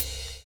DISCO 7 OH.wav